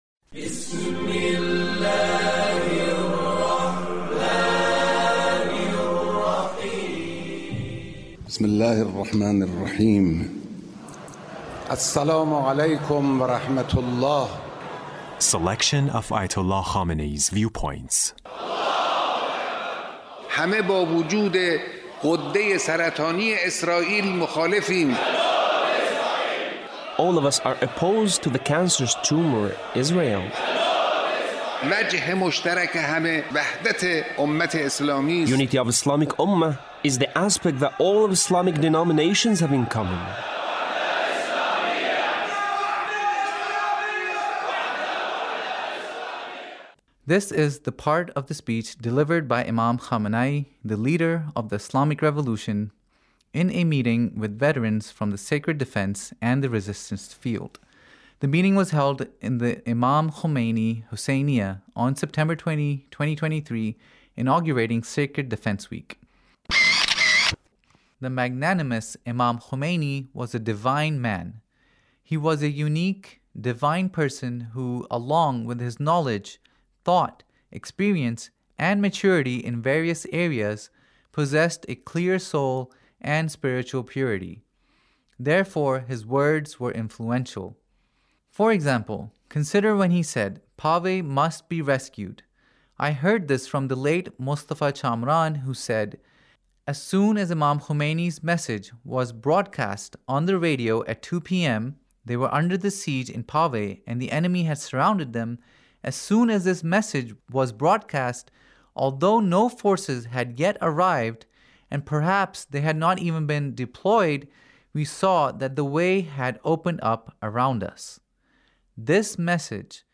Leader's Speech (1876)